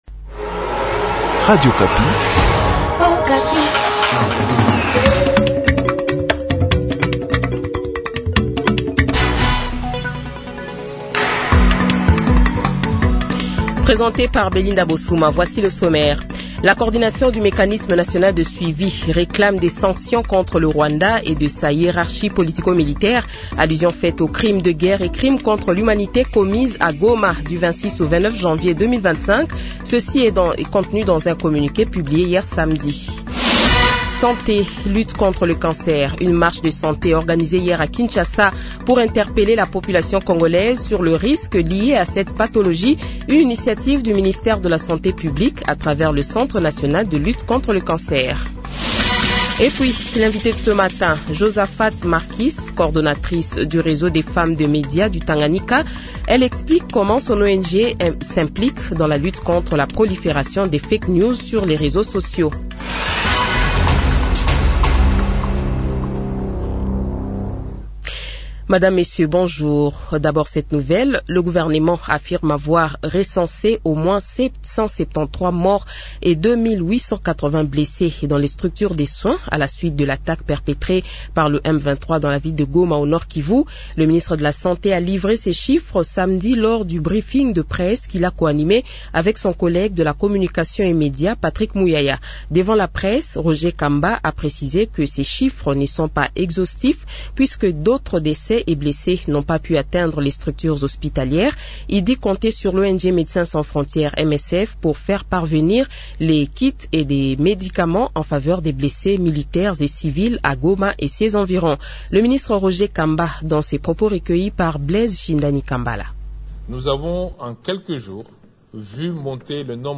Journal Francais Matin
Le Journal de 7h, 02 Fevrier 2025 :